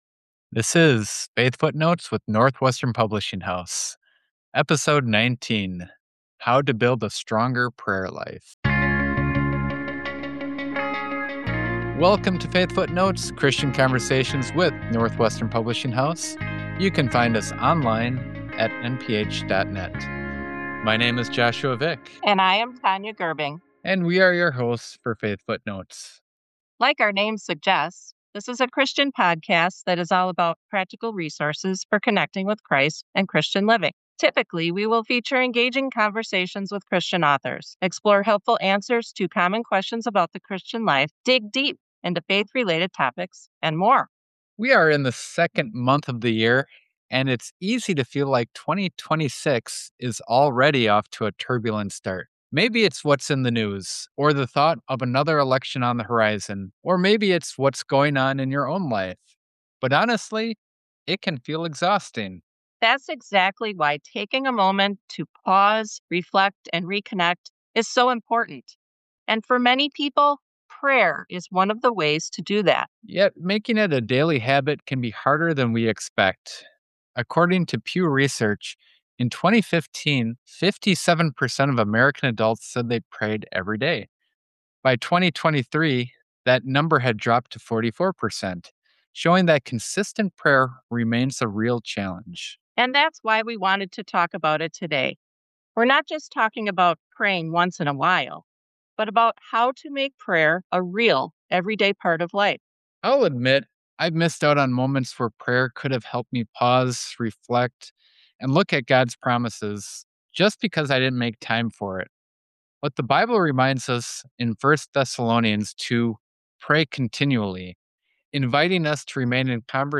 Whether you’re building a daily habit or learning how to begin, this conversation points you back to the confidence we have in Christ.